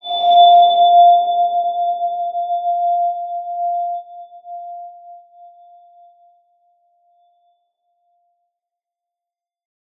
X_BasicBells-F3-mf.wav